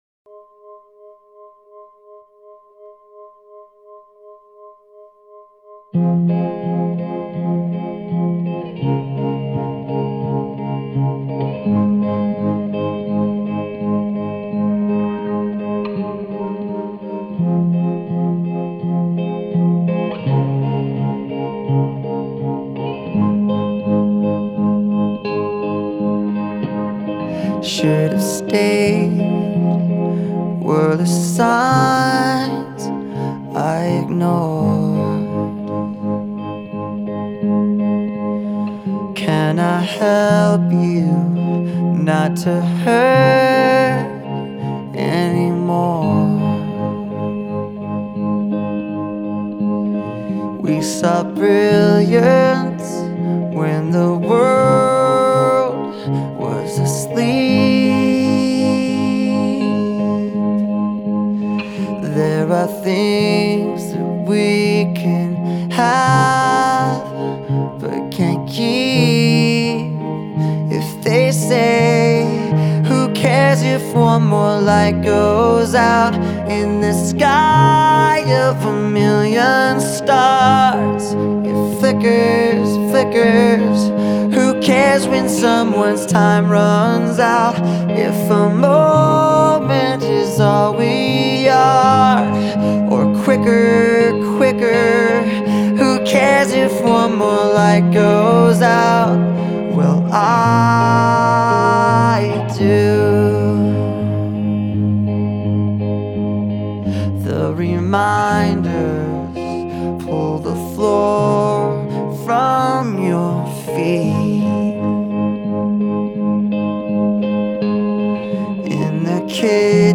• Жанр: Alternative, Rock